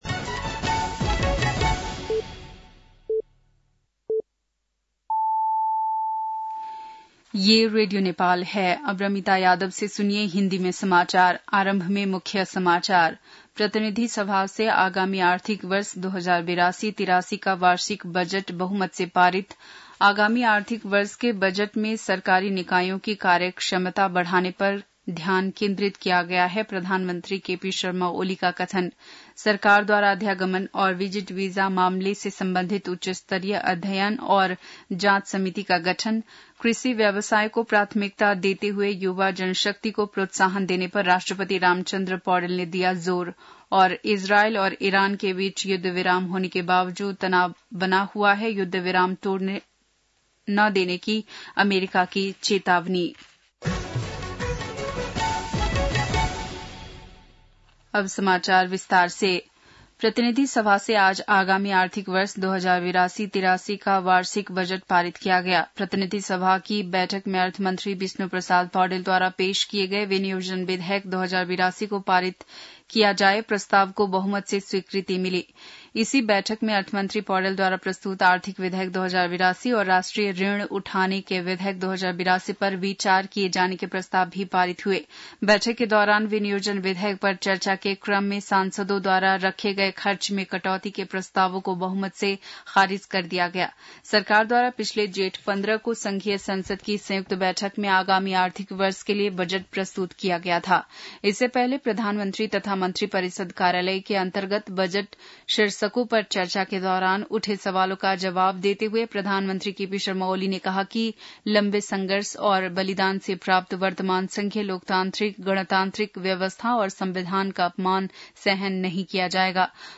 बेलुकी १० बजेको हिन्दी समाचार : १० असार , २०८२
10-pm-hindi-news-3-09.mp3